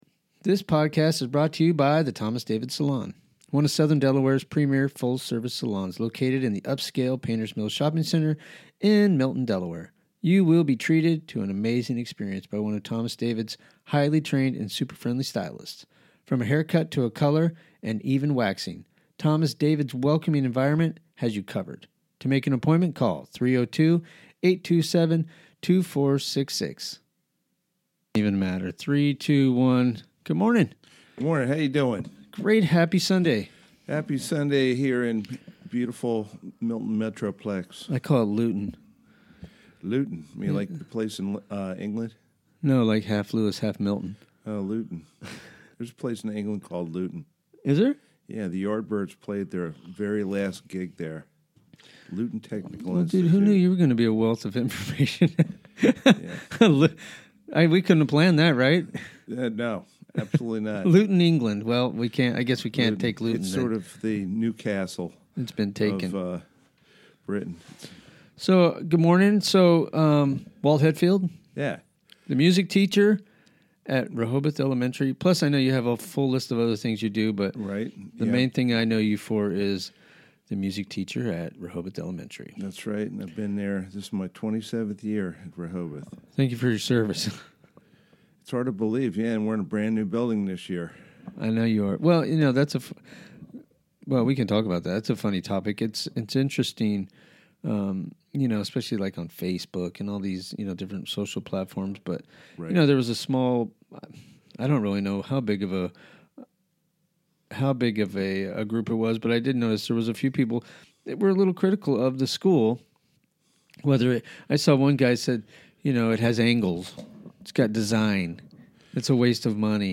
Chatting with local folks